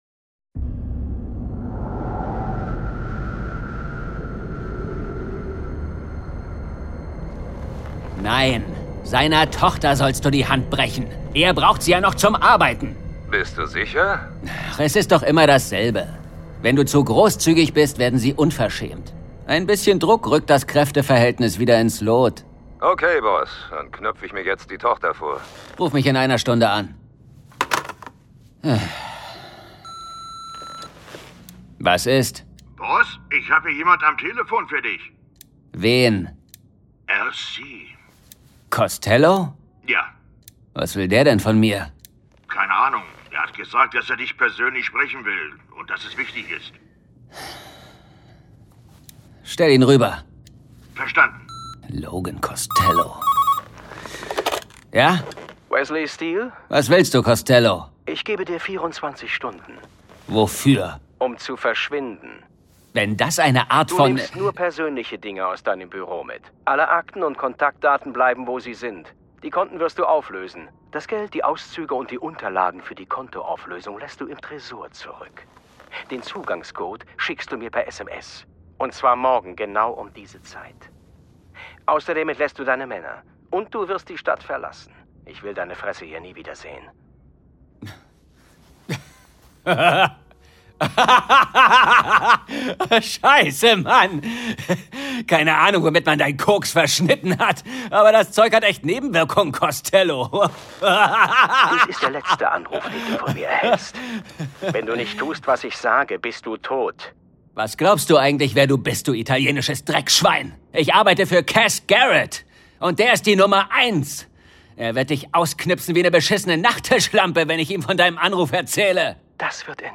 John Sinclair - Folge 68 Die Leichenkutsche von London. Hörspiel.